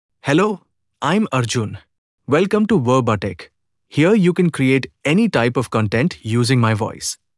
Arjun — Male English (India) AI Voice | TTS, Voice Cloning & Video | Verbatik AI
Arjun is a male AI voice for English (India).
Voice sample
Listen to Arjun's male English voice.
Arjun delivers clear pronunciation with authentic India English intonation, making your content sound professionally produced.